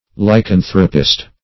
Lycanthropist \Ly*can"thro*pist\, n. One affected by the disease lycanthropy.